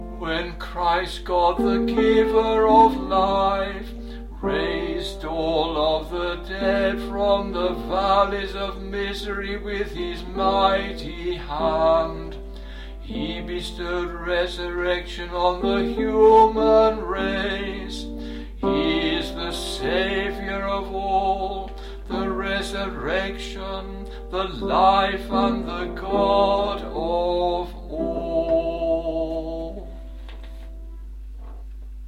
TONE 6 KONTAKION
tone-6-kontakion.mp3